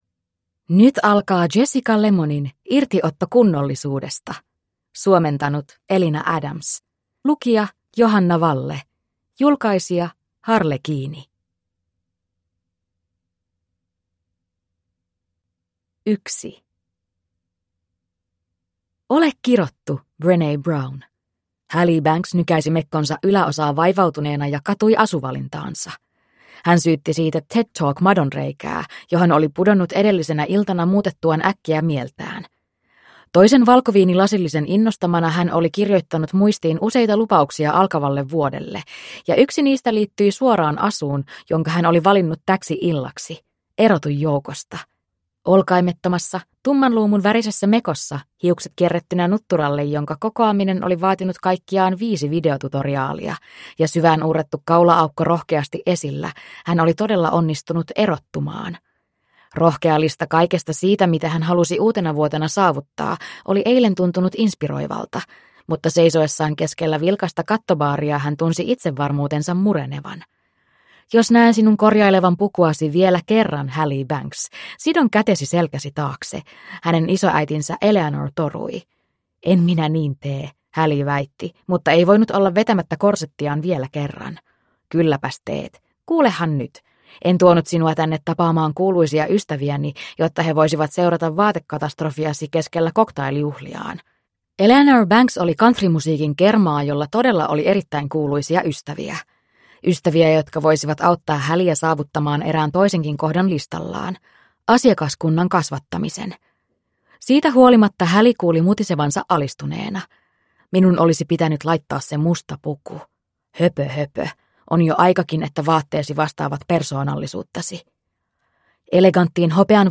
Irtiotto kunnollisuudesta (ljudbok) av Jessica Lemmon